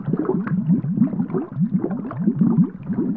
bubbletrail2.wav